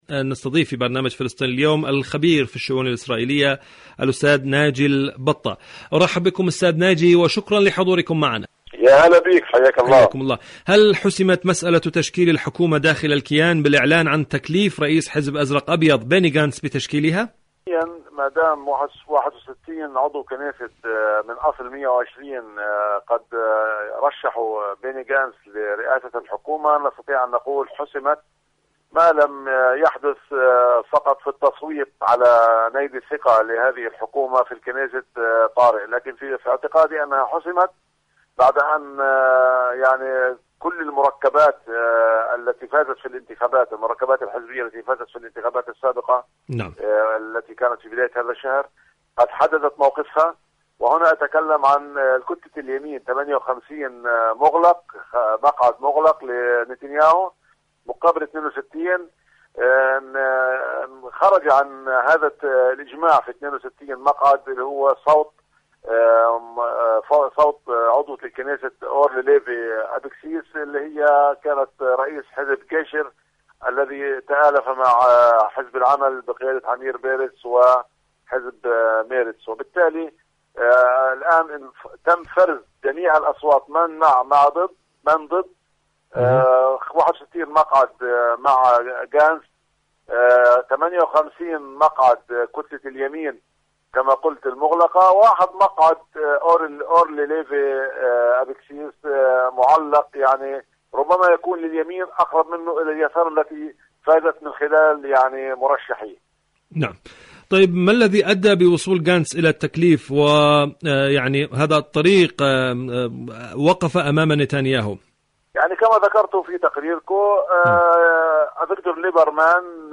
الكيان وحكومة أنصاف الحلول.. مقابلة